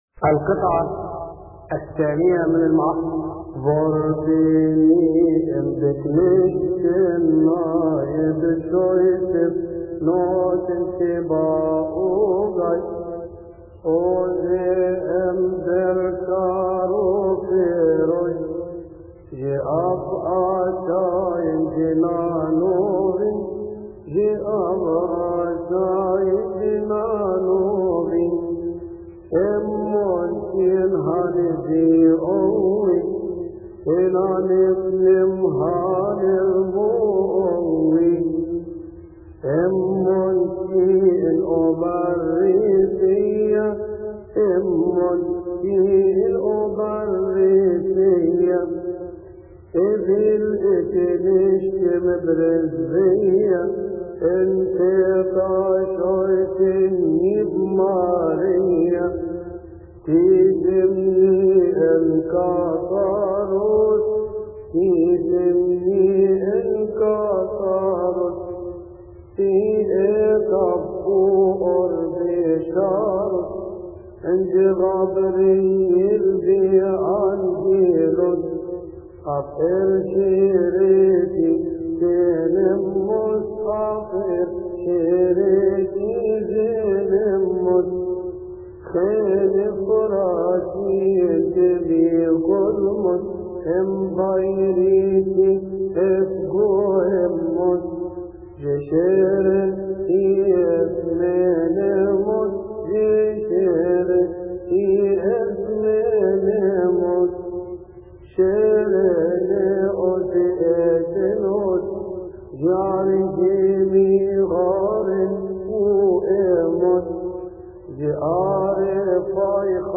• المصدر : المرتل
يصلي في تسبحة عشية أحاد شهر كيهك